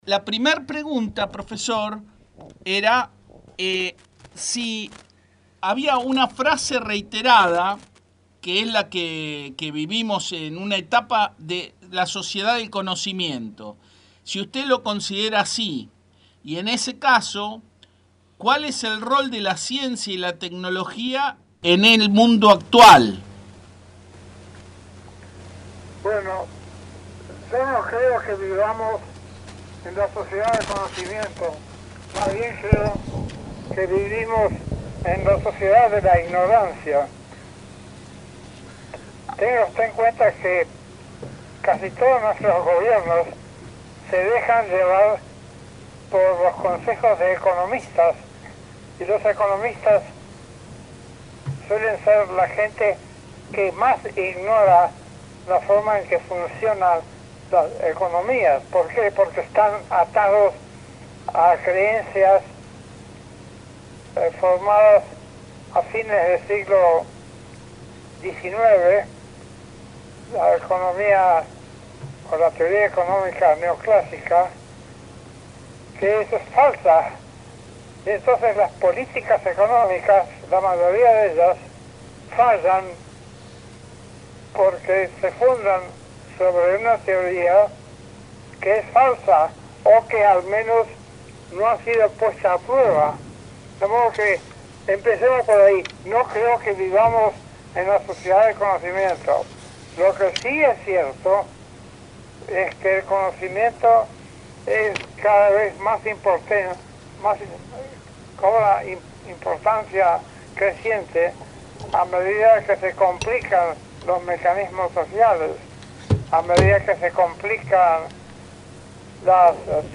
Entrevista completa al Dr. Mario Bunge